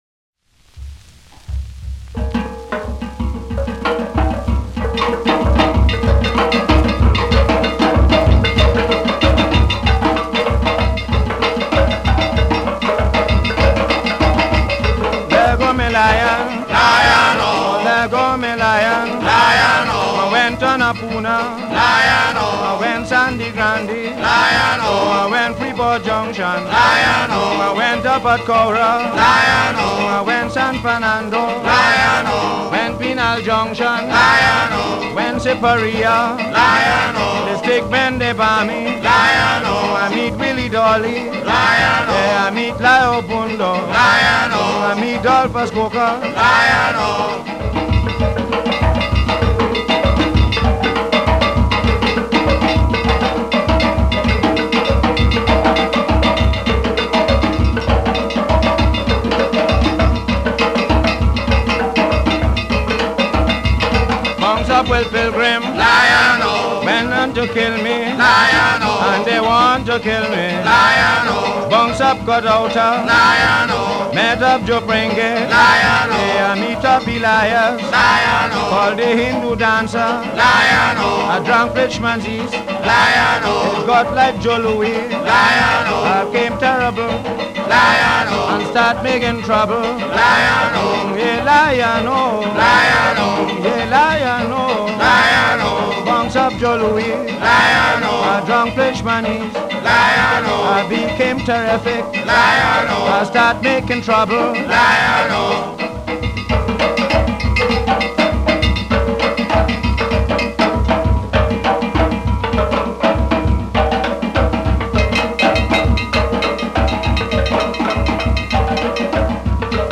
Lavway  - en äldre typ av musik för karnevalståg på gatan. Detta är en arkivinspelning från 1940 med enbart metallslagverk d.v.s. ett tidigt steelband.  Försångaren är kaisonian The Roaring Lion.